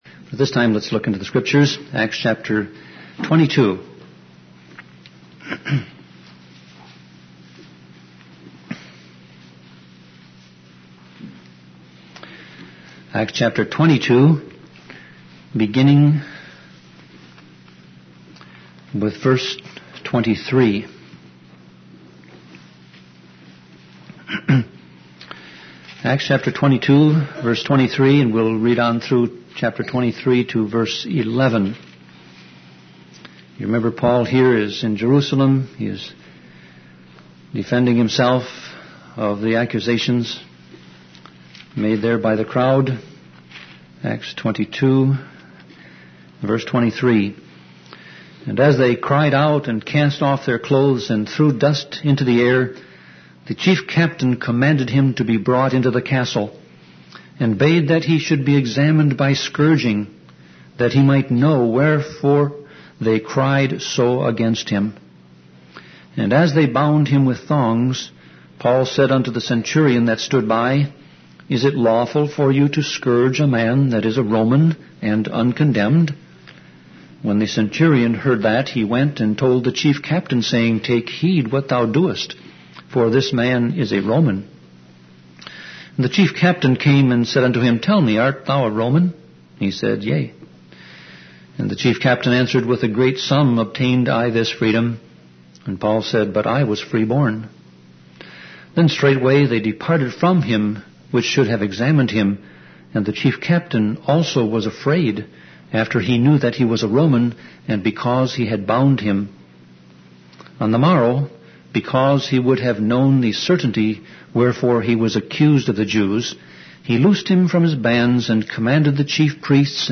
Sermon Audio Passage: Acts 22:23-23:11 Service Type